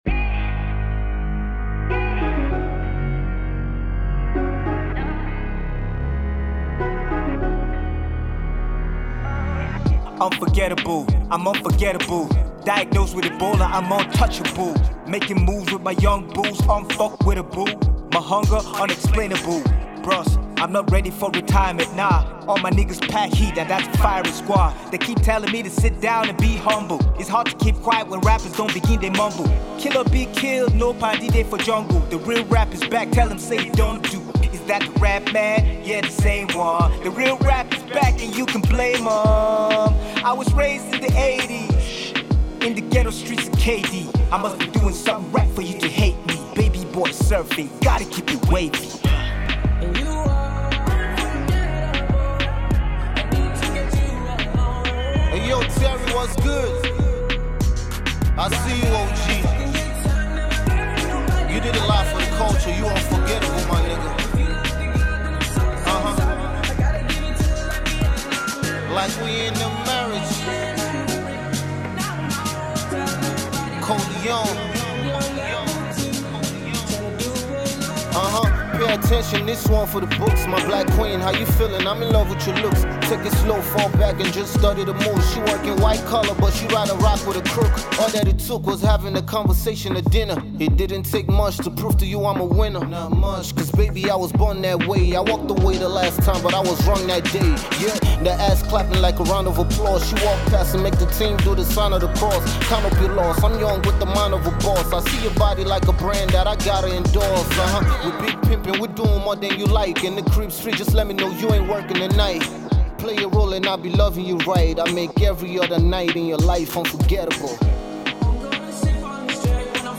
rap version